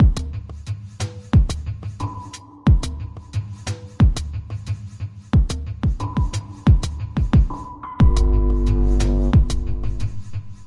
Ambient Groove " Ambient Groove 008
描述：为环境音乐和世界节奏制作。完美的基础节拍。
Tag: 环境 循环